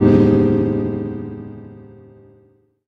終了音